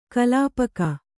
♪ kalāpaka